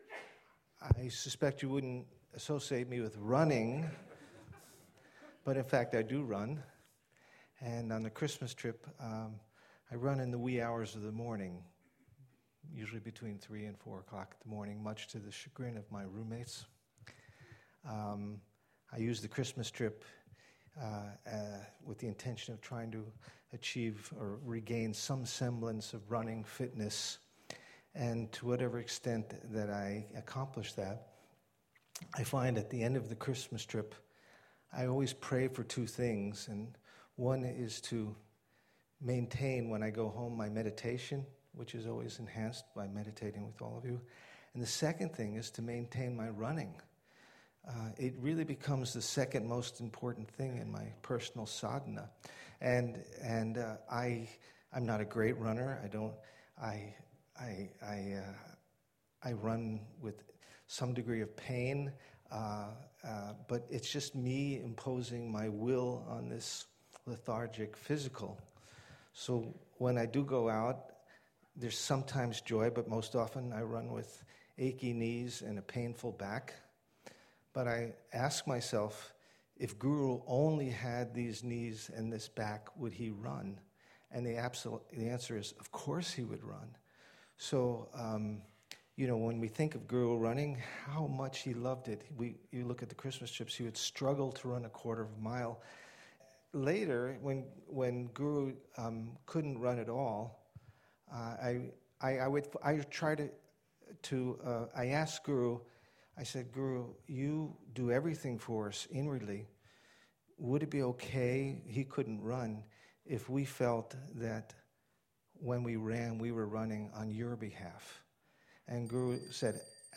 The talks
At one evening during this years Trip, 25 of Sri Chinmoy’s students were invited to give 2 minute talks on different aspects of our guru’s spiritual path. Here we present 10 of those talks – on singing, running and meditating on Sri Chinmoy’s transcendental photograph.